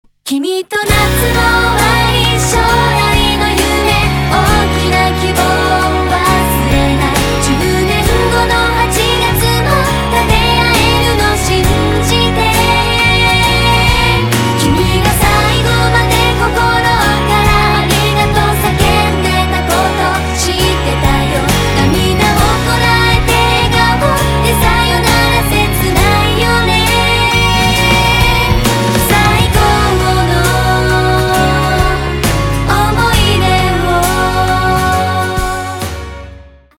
• Качество: 192, Stereo
женский вокал
японские